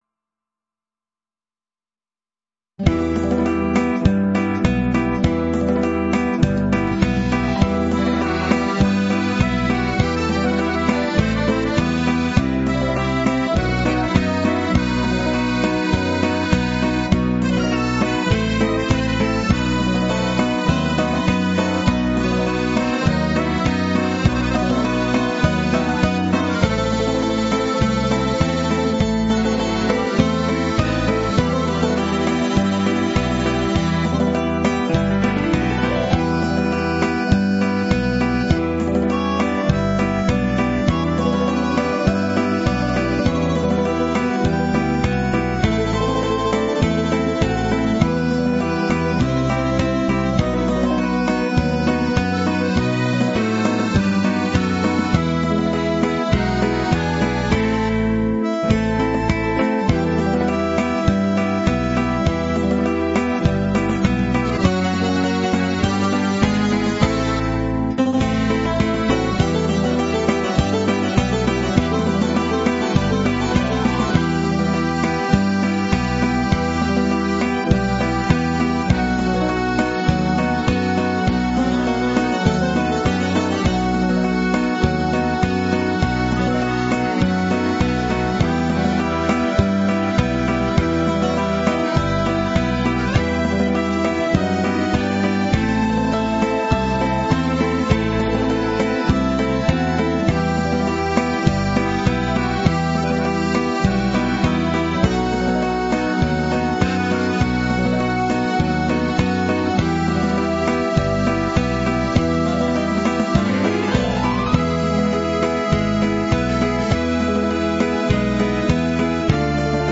Greek Jazz